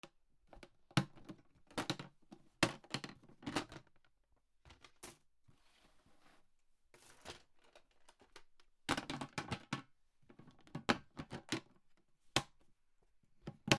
プラスチックの箱を開いてまた戻す
『カカタ』